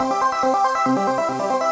Index of /musicradar/shimmer-and-sparkle-samples/140bpm
SaS_Arp02_140-C.wav